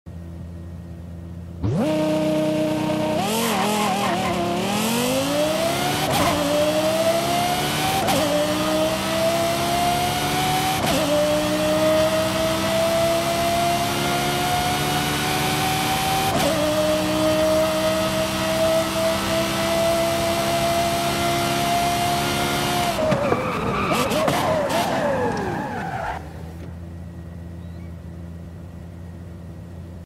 2010 Lexus LFA Launch Control sound effects free download
2010 Lexus LFA Launch Control & Sound - Forza Horizon 5